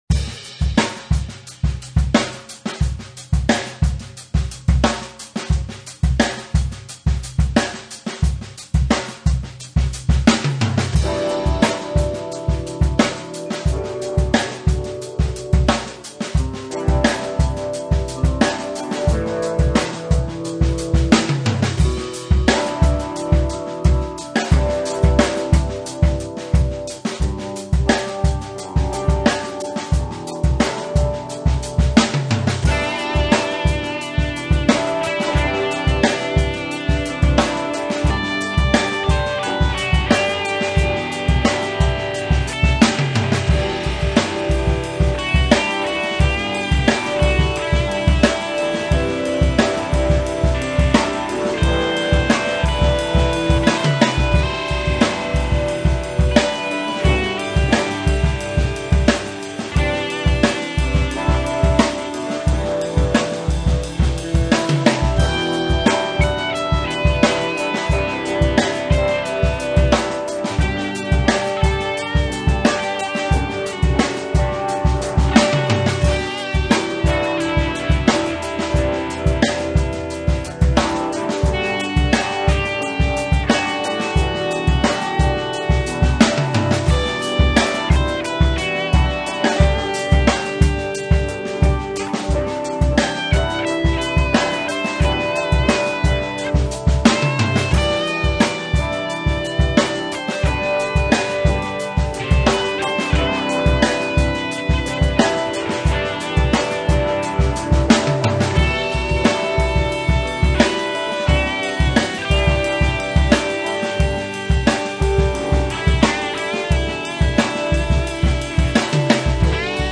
I simply looked for some beats, then played something along to them.
And since it's always random stuff... improvisation, there are always mistakes.
For an example of an less serious recording/improvisation, have an ear full of the attachment (as i said... there are mistakes, i've warned you guys!).
It's still low quality tho, since i had to put it down to 80kbit to fit the max. attachment size of the forum.
And im listning to your song, its really nice and soft I like it.